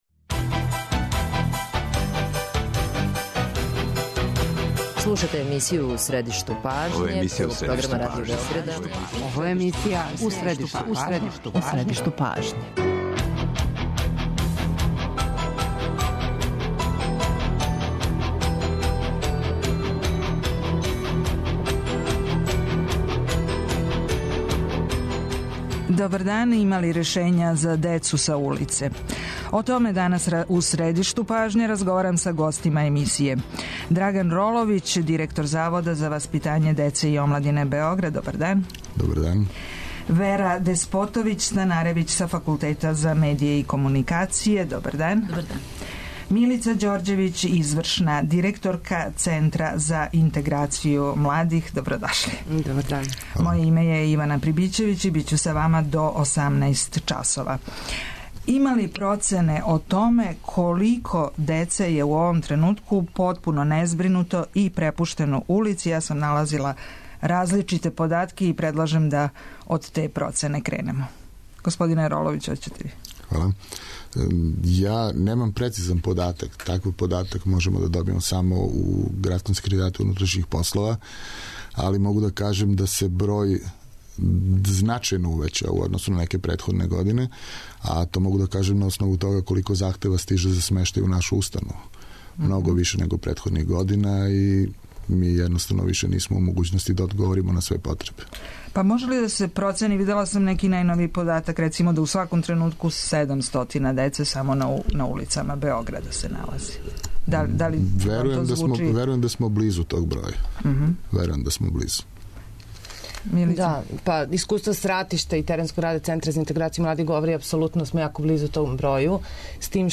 доноси интервју са нашим најбољим аналитичарима и коментаторима, политичарима и експертима